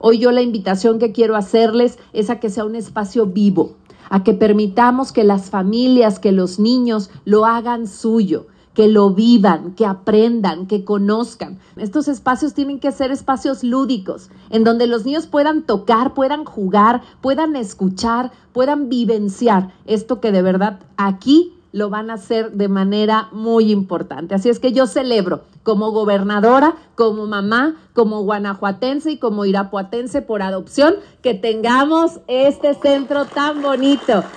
La inauguración del Centro de Educación Ambiental, se dio como parte de los festejos por el 478 aniversario de la fundación de Irapuato.